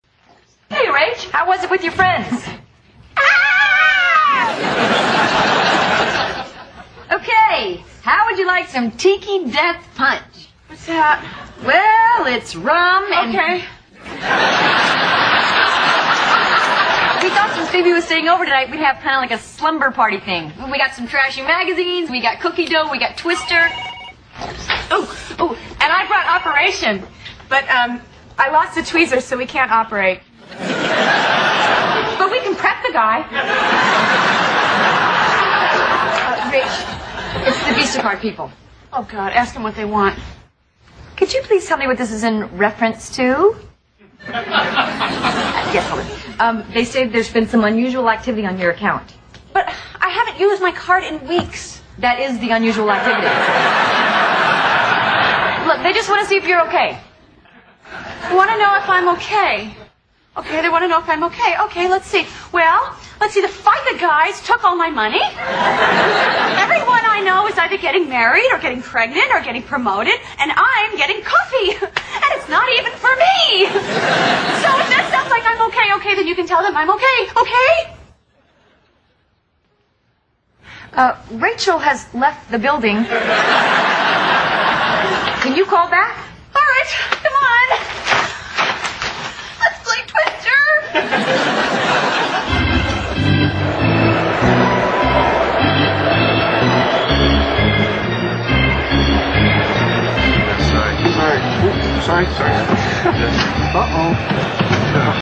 在线英语听力室老友记精校版第1季 第38期:克林顿亲信助手(6)的听力文件下载, 《老友记精校版》是美国乃至全世界最受欢迎的情景喜剧，一共拍摄了10季，以其幽默的对白和与现实生活的贴近吸引了无数的观众，精校版栏目搭配高音质音频与同步双语字幕，是练习提升英语听力水平，积累英语知识的好帮手。